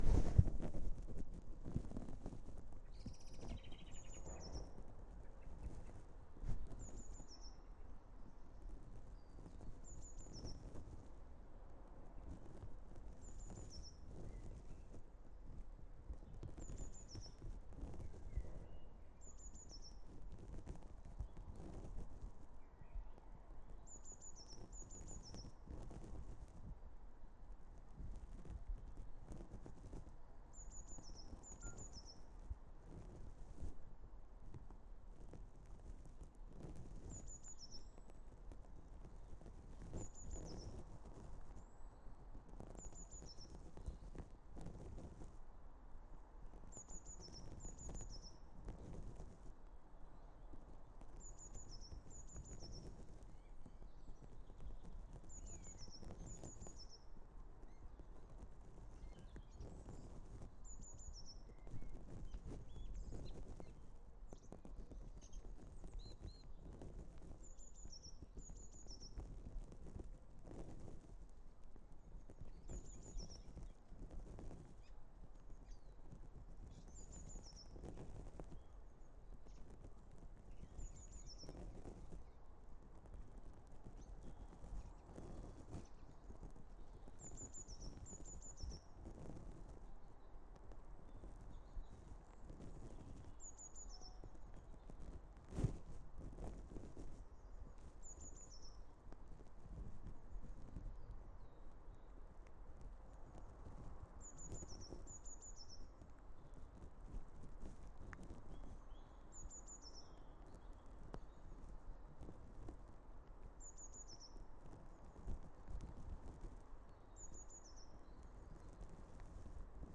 Easter Monday Birds 1. anyone know what loud bird in first part is, please?